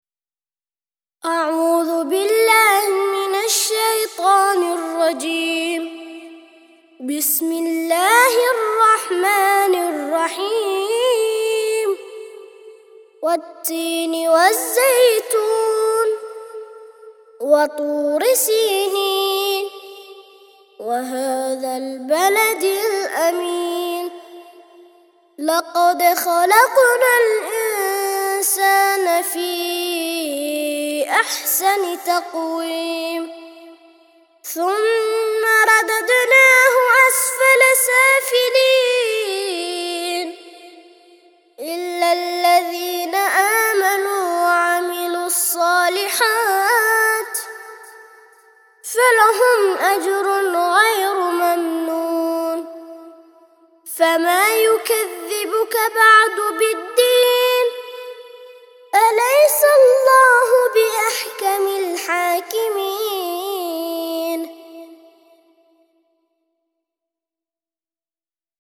95- سورة التين - ترتيل سورة التين للأطفال لحفظ الملف في مجلد خاص اضغط بالزر الأيمن هنا ثم اختر (حفظ الهدف باسم - Save Target As) واختر المكان المناسب